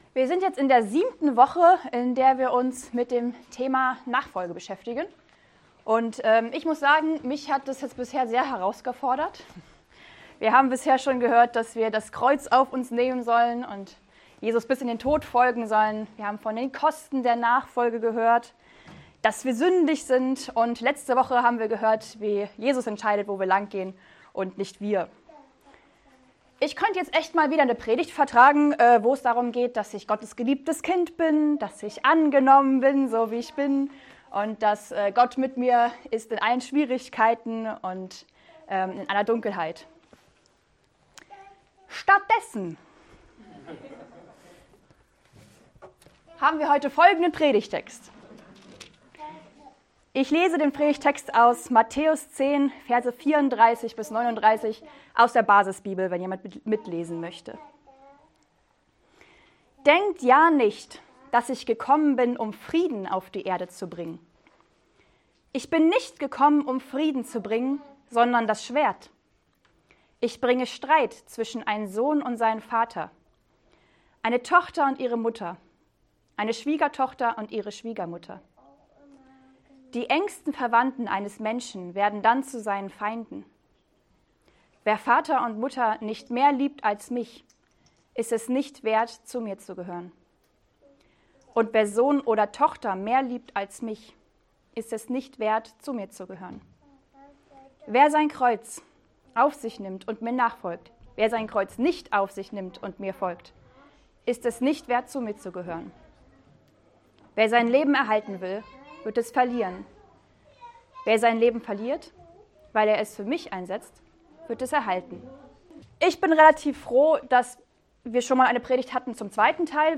Eine predigt aus der serie "GreifBar+."